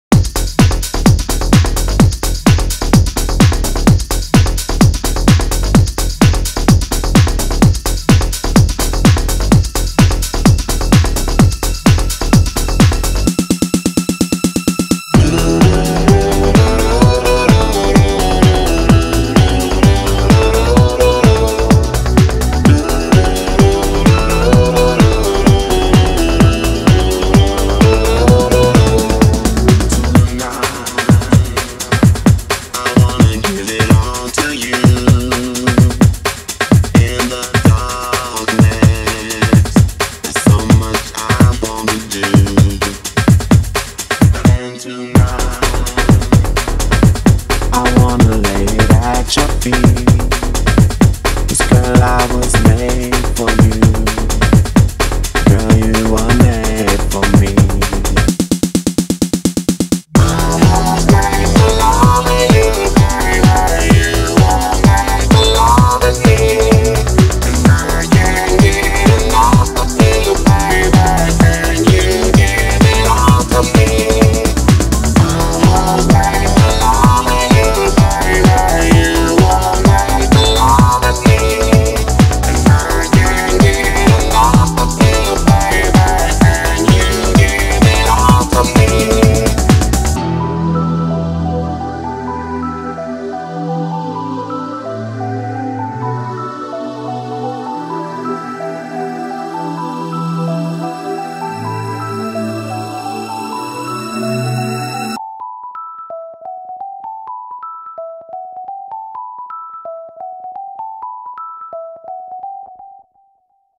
BPM128
Audio QualityPerfect (High Quality)
A Techno House cover